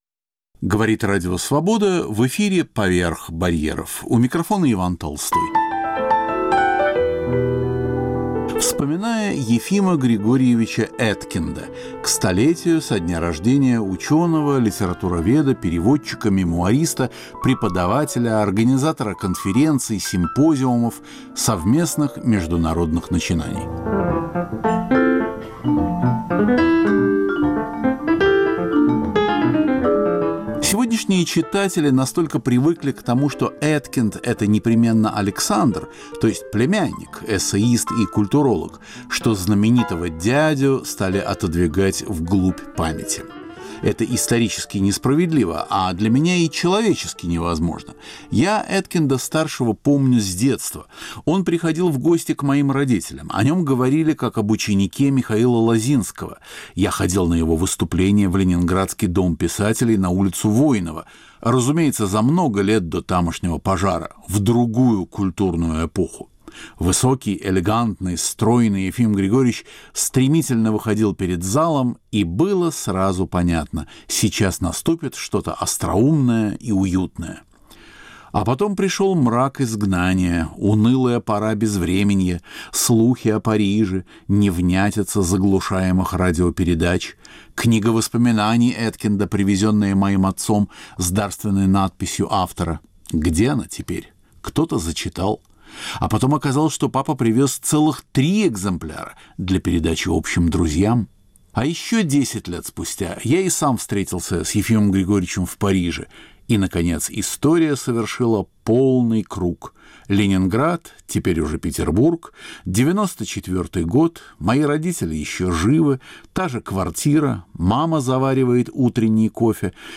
Вспоминая Ефима Григорьевича: К 100-летию со дня рождения Ефима Эткинда - литературоведа, переводчика, мемуариста, преподавателя. Звучит голос ученого и размышления его друзей.